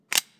slide.wav